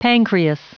Prononciation du mot pancreas en anglais (fichier audio)
pancreas.wav